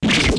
Gun03.mp3